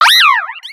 Cri de Mesmérella dans Pokémon X et Y.